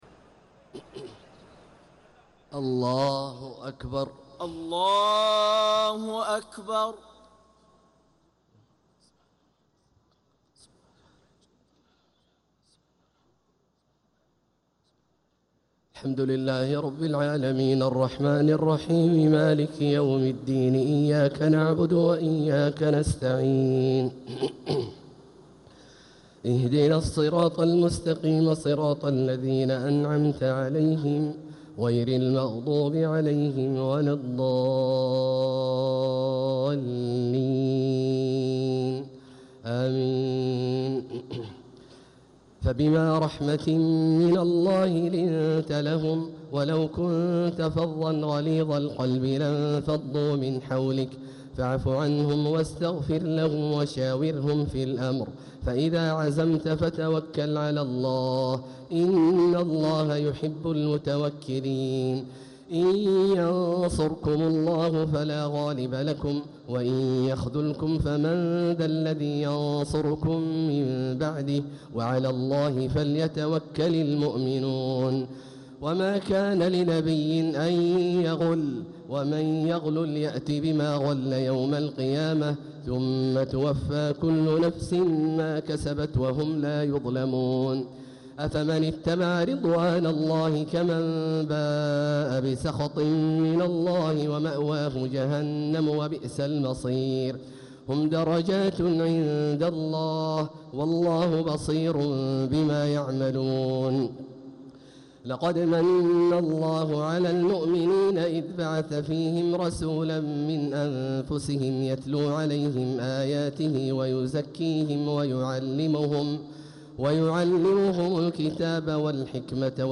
تلاوة من سورة آل عمران (159-200) | تراويح ليلة 5 رمضان 1447هـ > تراويح 1447هـ > التراويح - تلاوات عبدالله الجهني